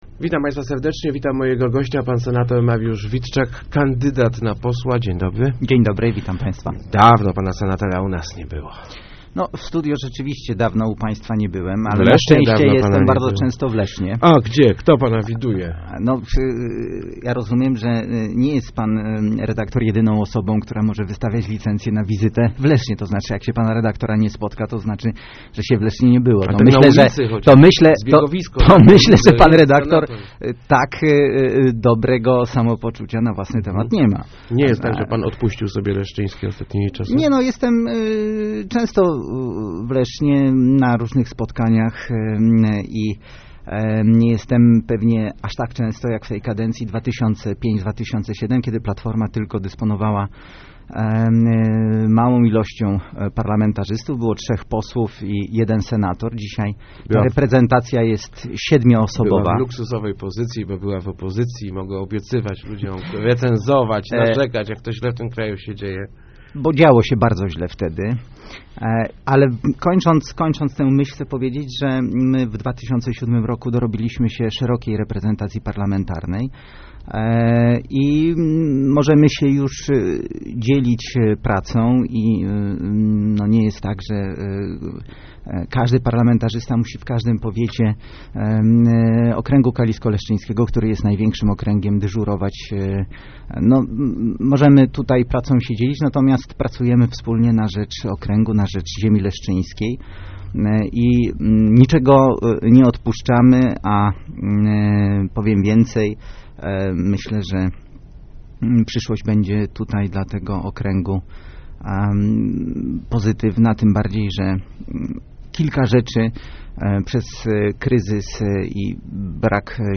Jestem entuzjastą budowy "Piątki" - zapewniał w Rozmowach Elki senator Mariusz Witczak, lider poselskiej listy PO w okręgu kalisko-leszczyńskim. Jednak na pytanie, czy ekpresówka po wyborach powstanie, odpowiada bardzo ostrożnie.